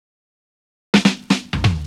Fill 128 BPM (14).wav